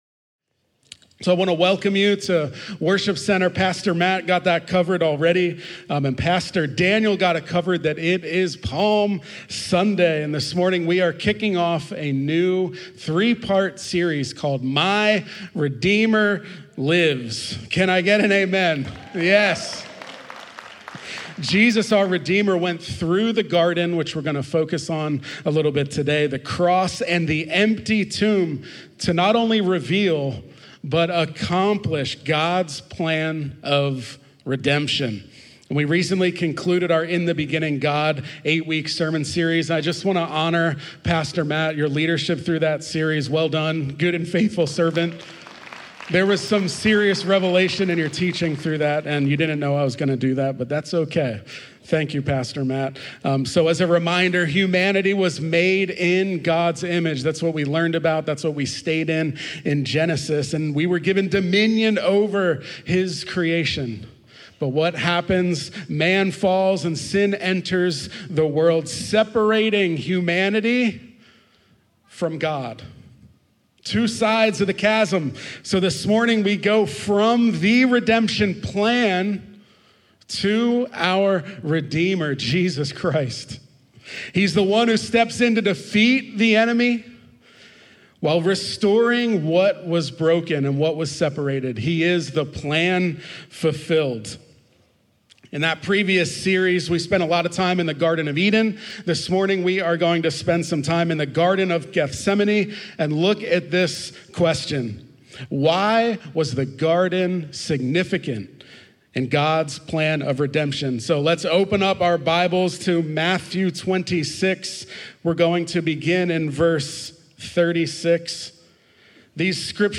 On Palm Sunday, Worship Center launched a new sermon series titled My Redeemer Lives, beginning with a focus on Jesus in the Garden of Gethsemane. Building on the previous Genesis series, this message highlighted how God’s redemptive plan—introduced in the Garden of Eden—was fulfilled in Jesus through His suffering, obedience, and sacrifice.